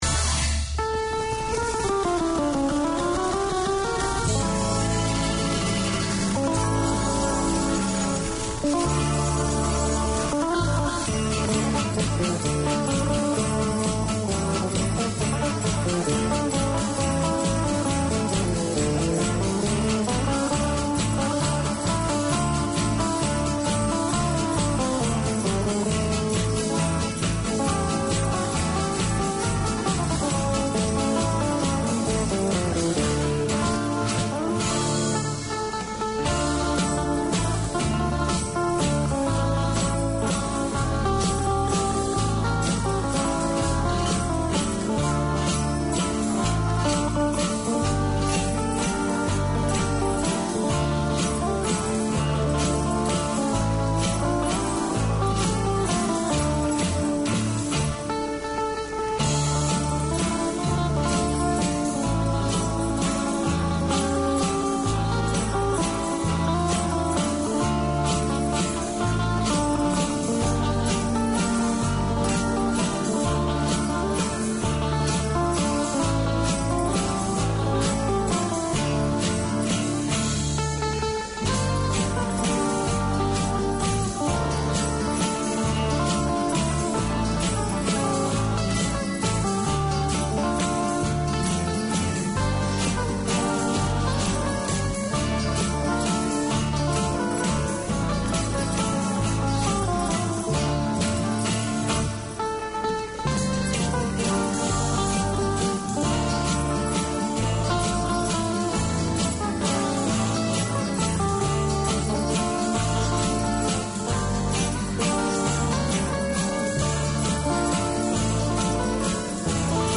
Community Access Radio in your language - available for download five minutes after broadcast.
The Filipino Show 12:40pm WEDNESDAY Community magazine Language